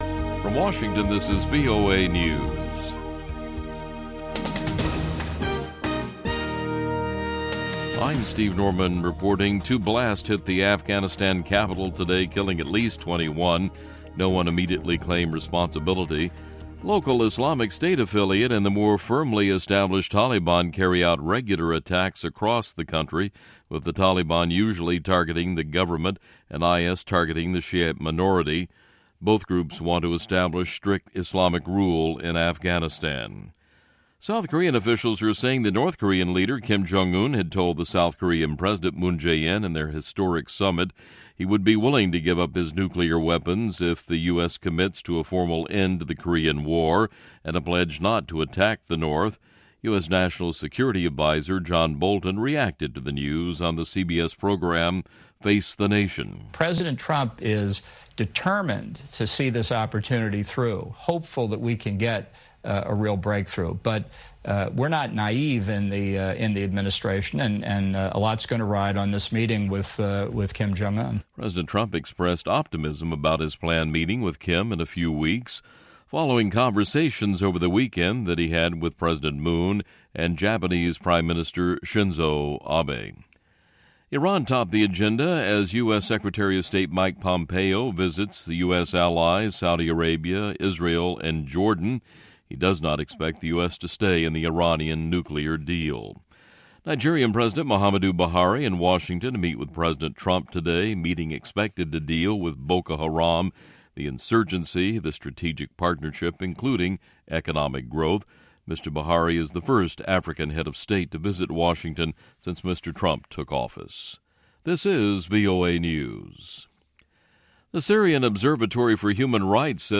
African Beat showcases the latest and the greatest of contemporary African music and conversation.